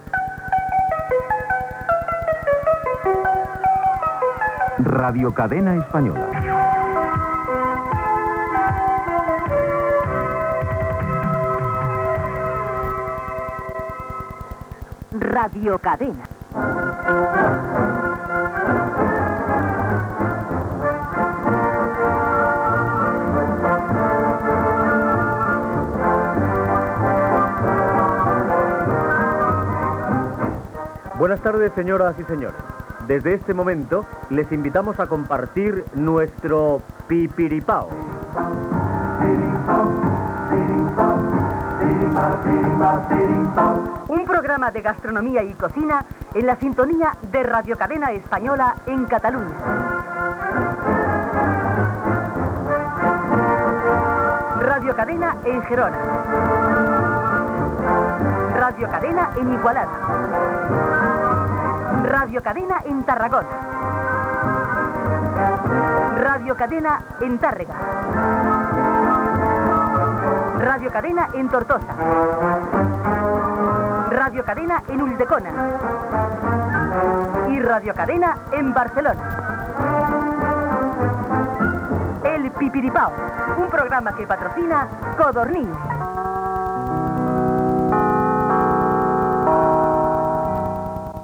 Indicatiu i careta del programa esmentant totes les emissores de Radiocadena Catalunya.